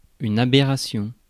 Ääntäminen
IPA: [a.bɛ.ʁa.sjɔ̃]